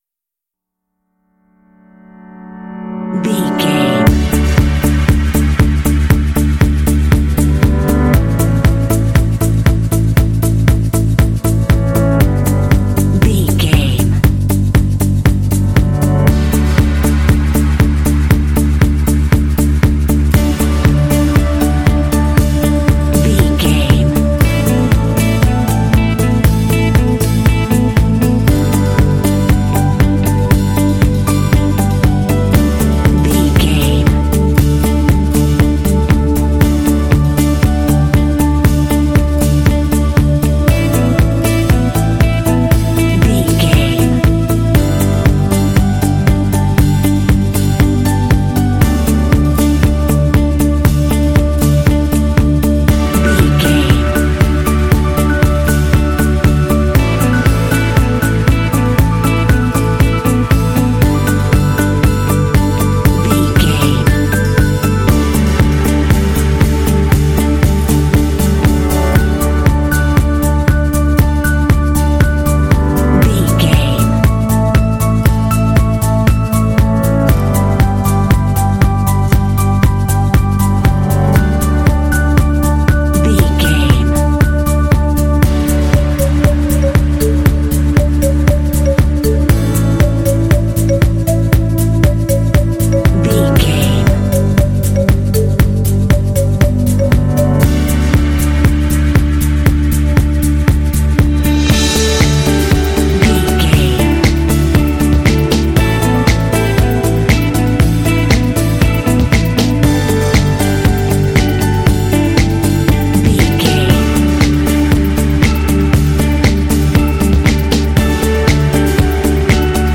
Uplifting
Ionian/Major
motivational
drums
synthesiser
strings
bass guitar
piano
acoustic guitar
electric guitar
synth-pop
indie